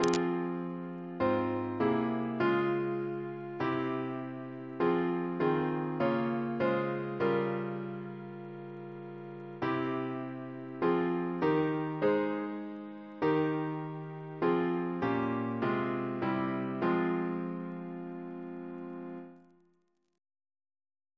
Double chant in F minor Composer